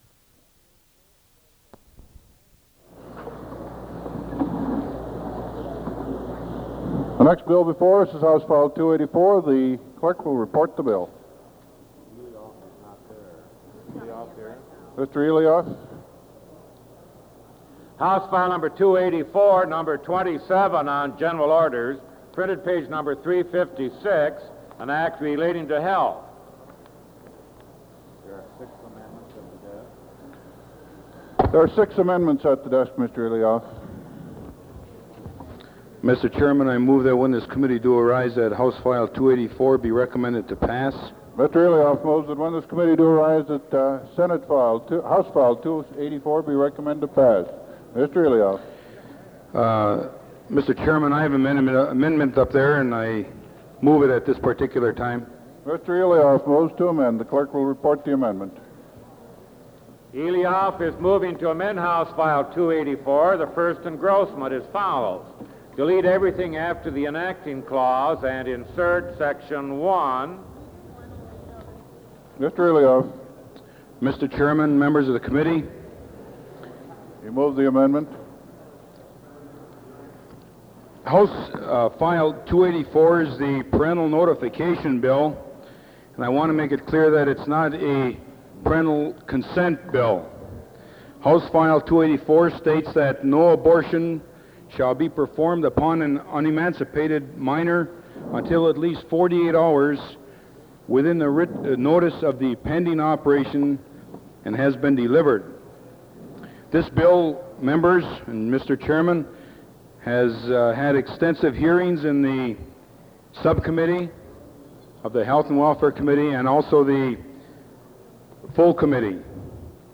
S.F. 287 and S.F. 110 Location Internet Legislative history audio recordings, Covers legislative hearings on H.F. 294 and S.F. 287, the parental notification bills.